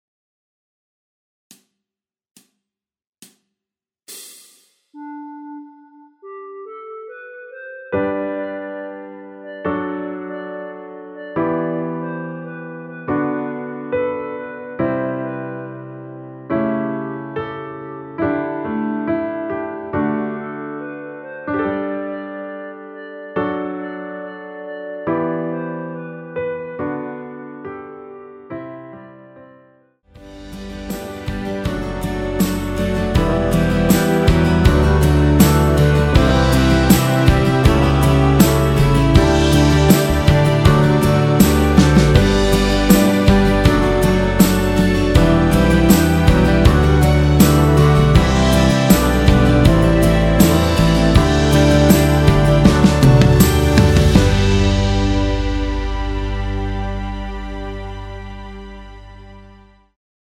노래가 바로 시작하는곡이라 카운트 넣어 놓았으며
원키에서(-2)내린 멜로디 포함된 MR입니다.
앞부분30초, 뒷부분30초씩 편집해서 올려 드리고 있습니다.
중간에 음이 끈어지고 다시 나오는 이유는